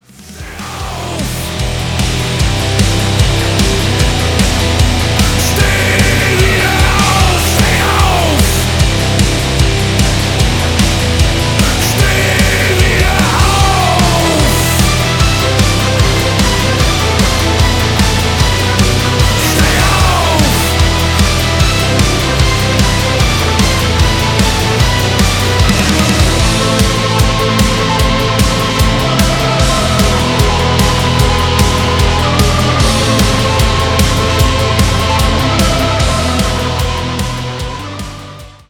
• Качество: 320, Stereo
громкие
Драйвовые
Industrial metal
Neue Deutsche Harte